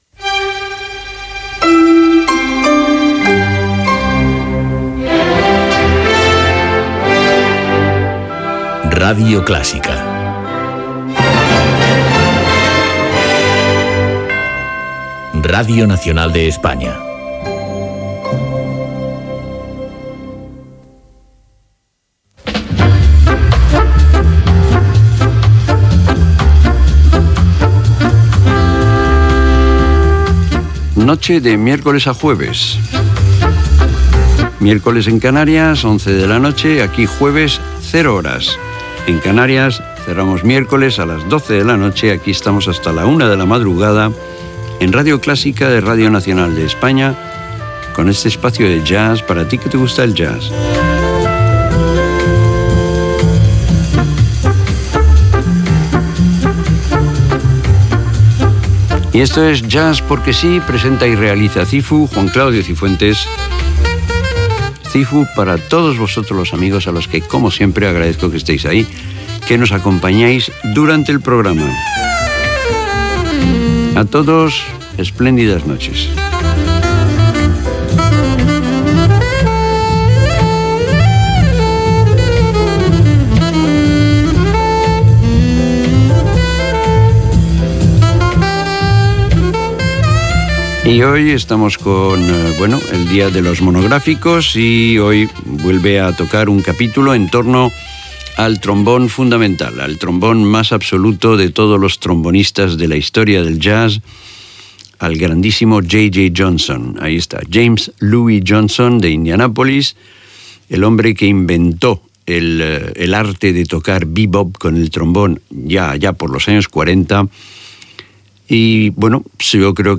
Indicatiu de l'emissora, sintonia, hora, presentació, espai dedicat al trombonista James Louis Johnson
Musical